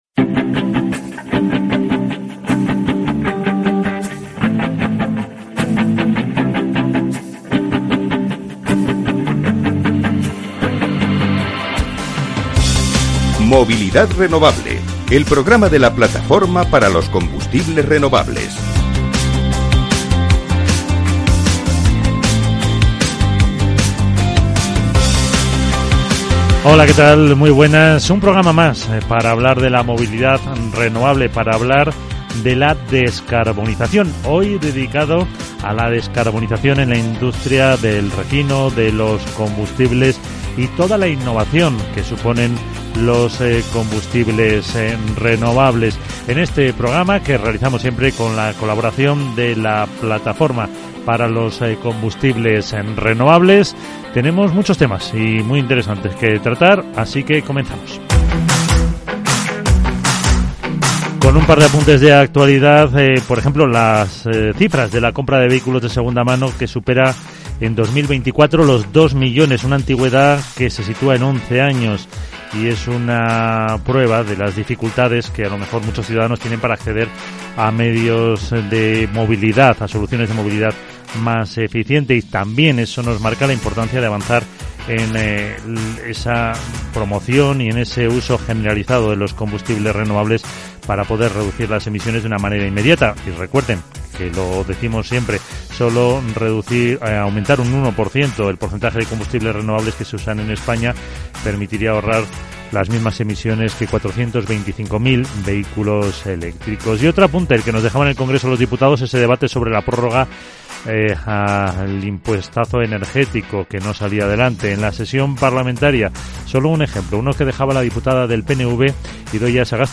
Movilidad Renovable es un programa realizado con la colaboración de la Plataforma para los Combustibles Renovables y que tiene como misión dar a conocer su importancia para tener una movilidad sostenible y ecológica. En este espacio van a participar todos los actores implicados desde los productores hasta los consumidores, pasando por distribuidores etc. Media hora de radio enfocada a conocer esta solución inmediata para mejorar el medio ambiente.